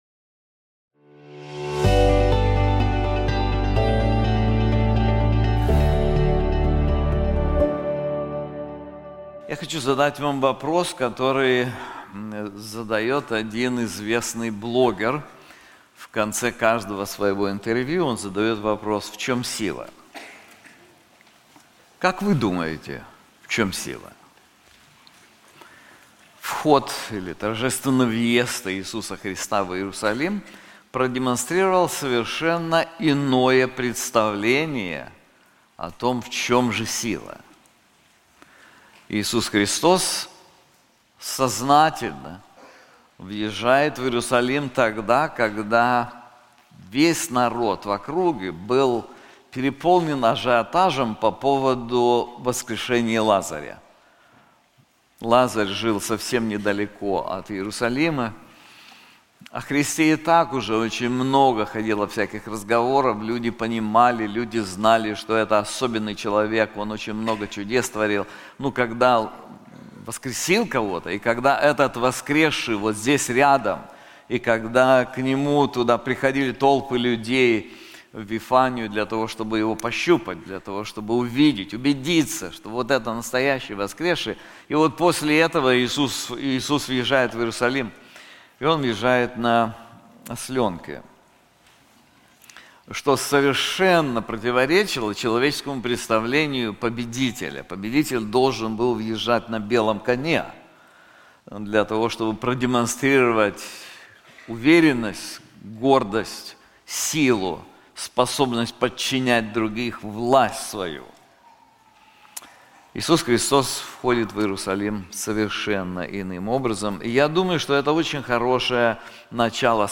This sermon is also available in English:The Power of the Righteous One • Isaiah 53:7-9